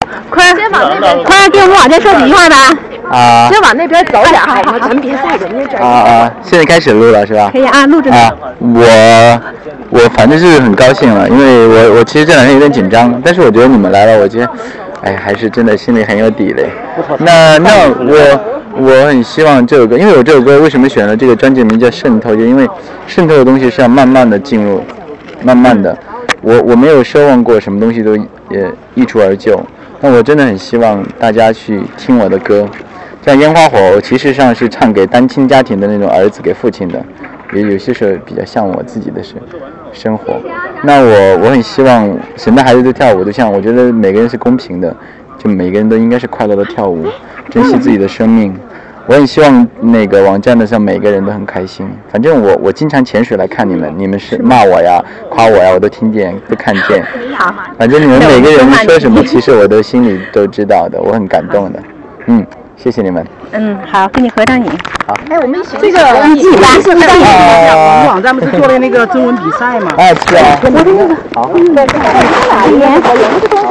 [04年留言] （04年10月15北京）陈坤彩排结束后给网站的留言[音频]
04年10月15日陈坤专辑预签会前一天工体首唱，当天上午彩排结束后，给我们爱坤网的留言。